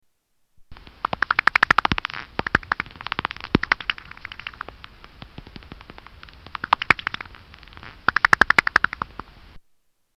Pipistrelle bat echolocation
Category: Animals/Nature   Right: Personal